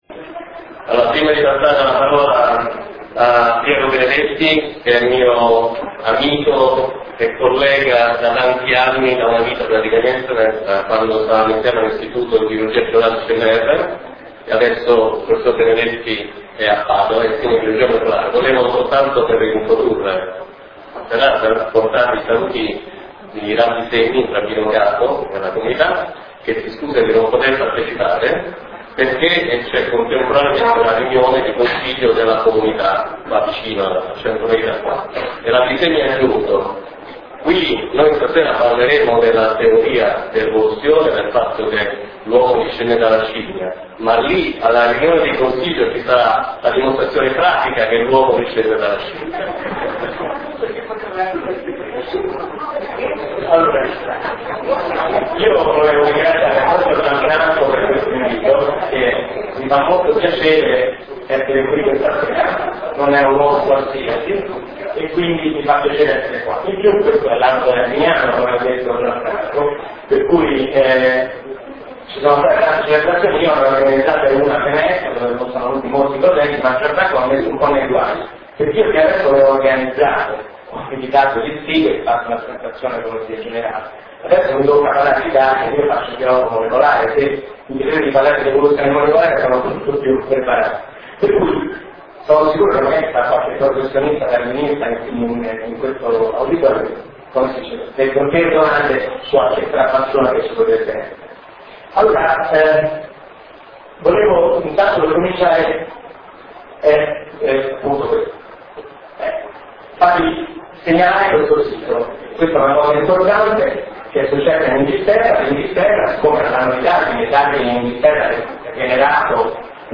Marted� 9 giugno 2009 - ore 21.00 Palazzo della Cultura, Via Portico d�Ottavia 73 Roma